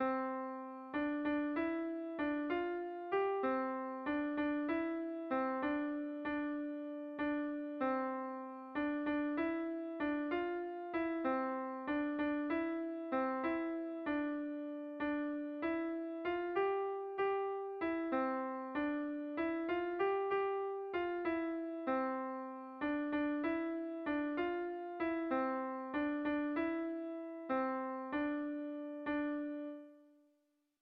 Bertso melodies - View details   To know more about this section
Tragikoa
Zortziko txikia (hg) / Lau puntuko txikia (ip)
AABA